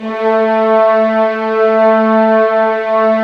Index of /90_sSampleCDs/Roland - Brass, Strings, Hits and Combos/ORC_Orc.Unison f/ORC_Orc.Unison f